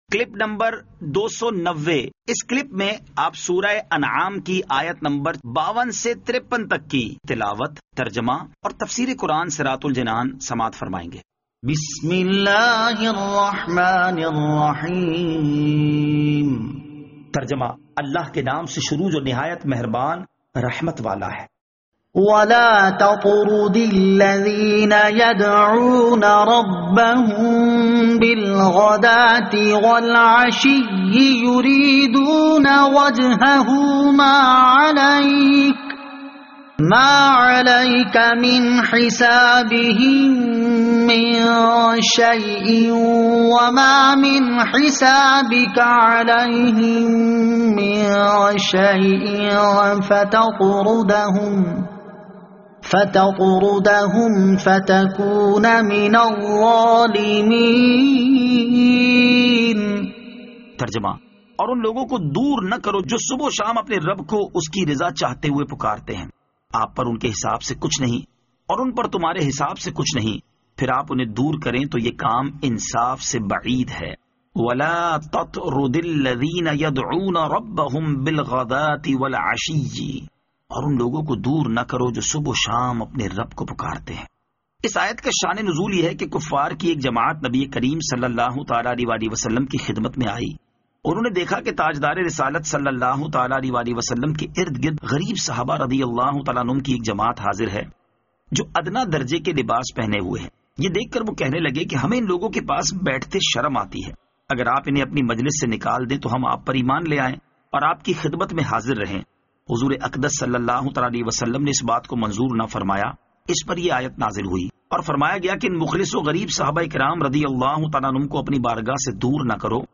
Surah Al-Anaam Ayat 52 To 53 Tilawat , Tarjama , Tafseer